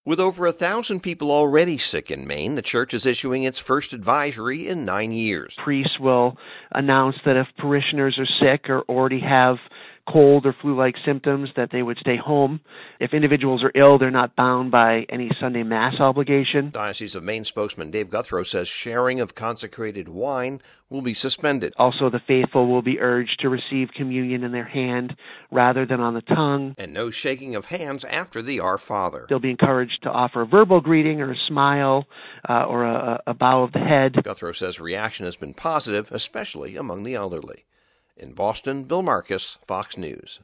(BOSTON) JAN 21 – NEW RULES FROM MAINE’S CATHOLIC CHURCH ATTEMPTING TO CONTAIN SPREAD OF THE FLU VIRUS. FOX NEWS RADIO’S